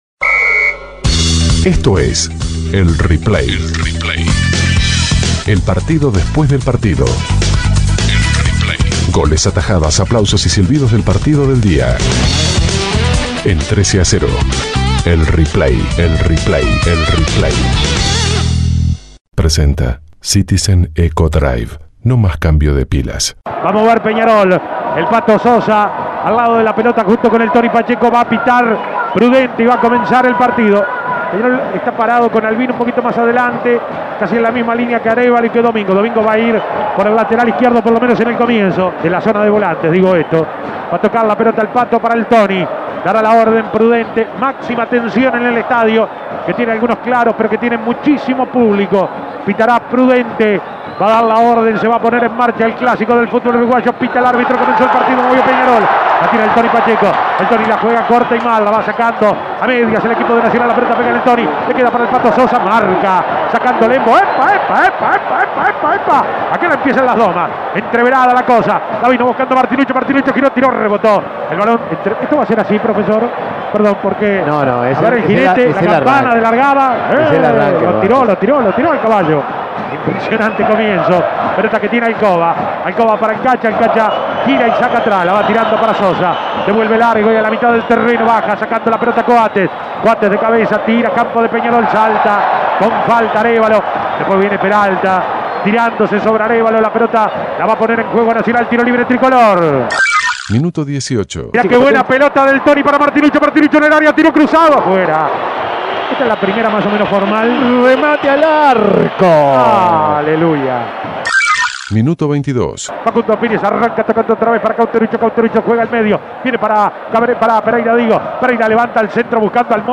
Goles y comentarios Escuche el replay de Nacional - Peñarol Imprimir A- A A+ Nacional y Peñarol empataron 0 a 0 por la doceava fecha del torneo Apertura.